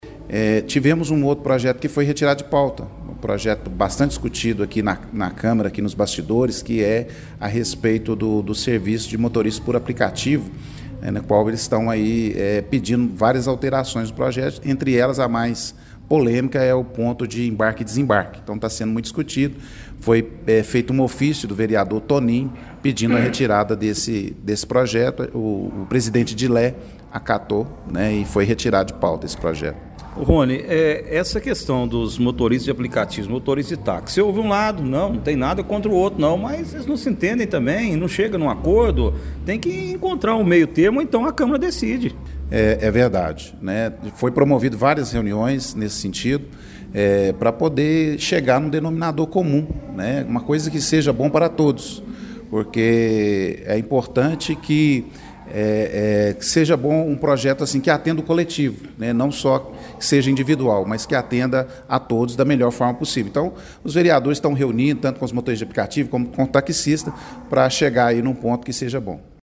A reunião de ontem (05) foi presidida pelo vereador Ronivelton Correia Barbosa, que também se manifestou sobre esta falta de entendimento entre motoristas de aplicativo e taxistas. Caso o Executivo Municipal não altere o projeto, os vereadores terão que decidir sobre a matéria, já que as partes não conseguem chegar a um acordo que satisfaça aos anseios das duas categorias: